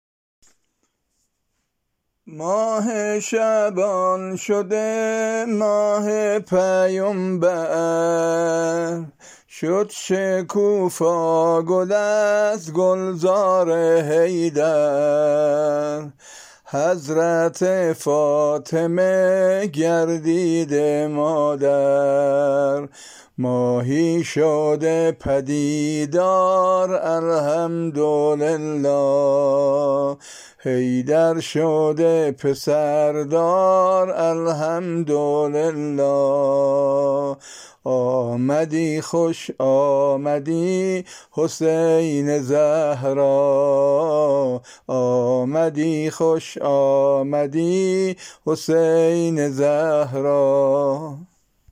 سرود میلاد امام حسین(ع)